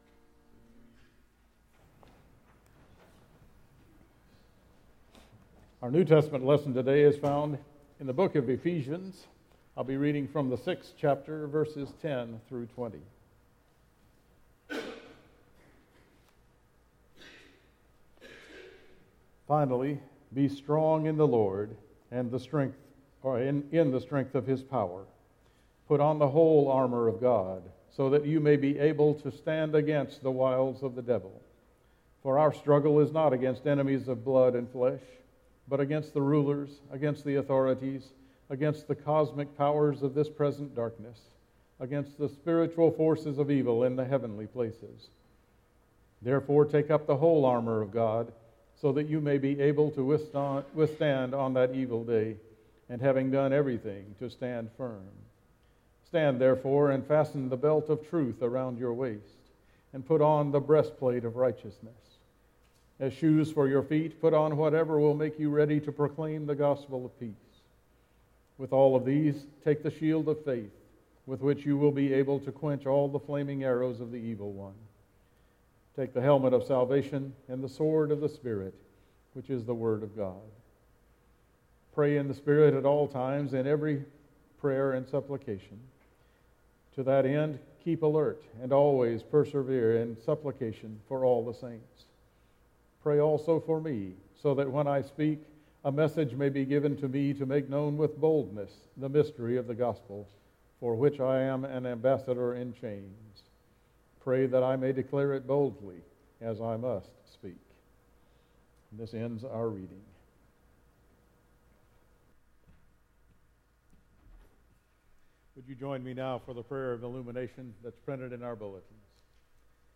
August 19 Worship Service